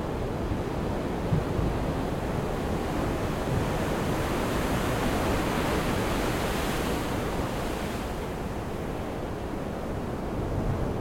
veter.ogg